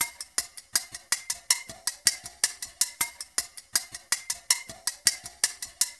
PercLoop (Fajita).wav